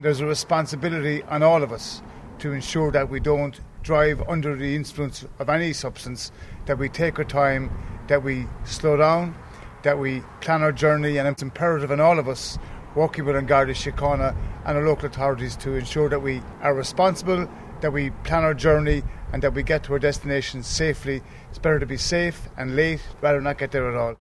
Junior Transport Minister Jerry Buttimer says everyone has a role to play in keeping our roads safe: